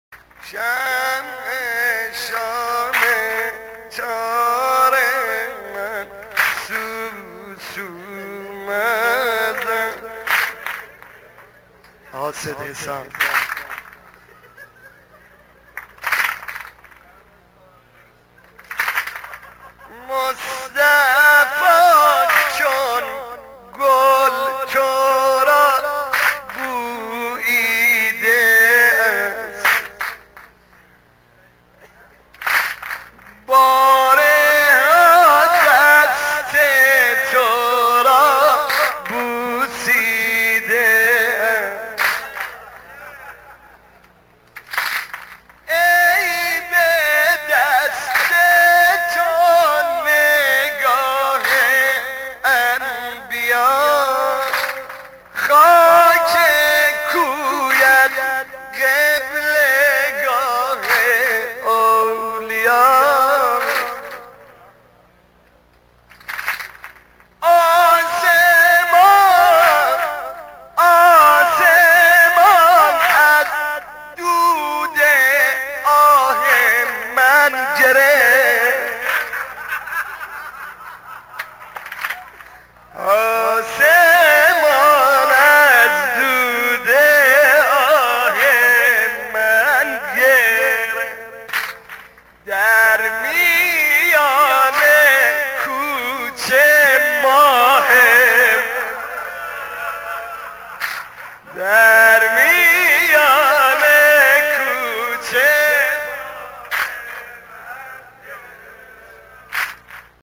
نوحه شهادت حضرت فاطمه زهرا(س